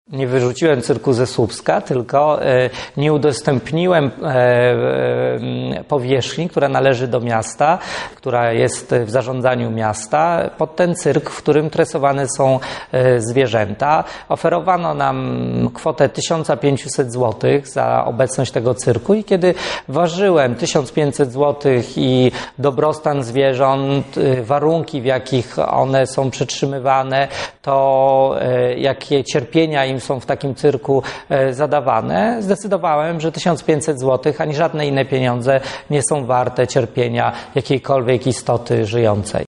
– mówił w wywiadzie dla TVN Robert Biedroń